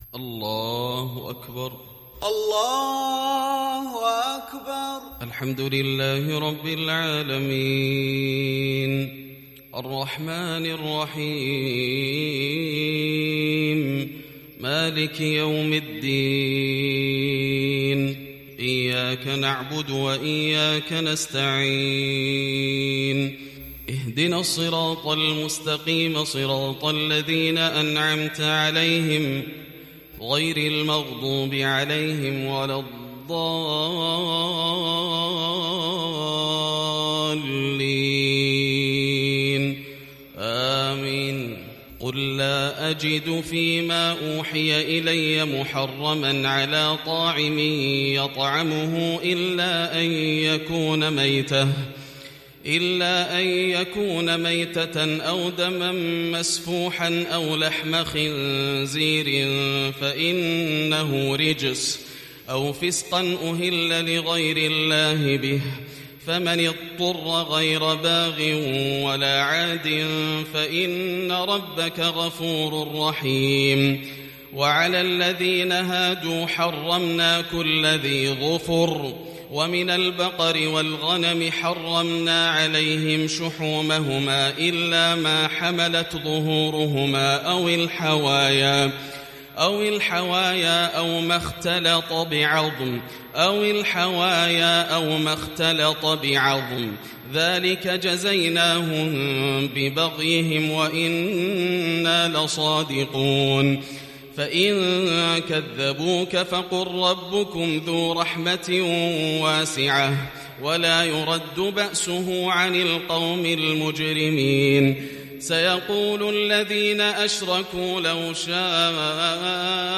صلاة الفجر للشيخ ياسر الدوسري 12 جمادي الآخر 1441 هـ
تِلَاوَات الْحَرَمَيْن .